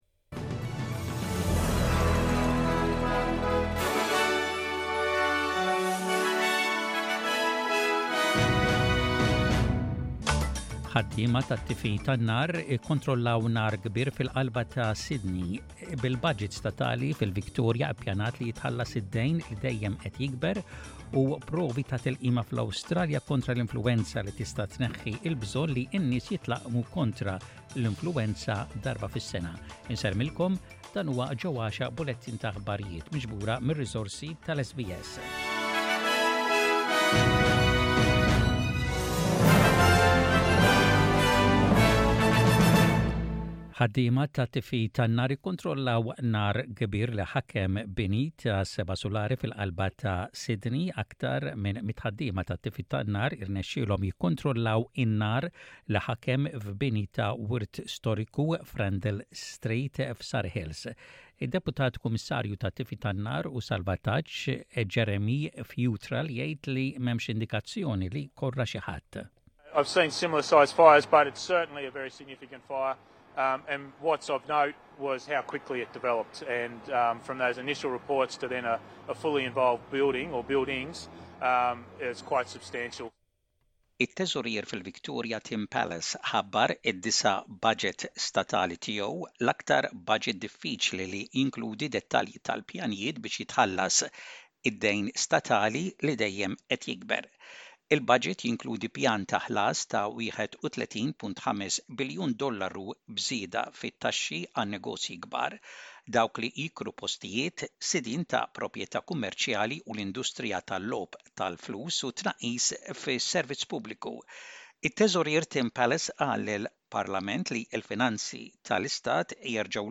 SBS Radio | Aħbarijiet bil-Malti: 26/05/23